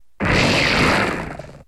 Grito de Barbaracle.ogg
Grito_de_Barbaracle.ogg.mp3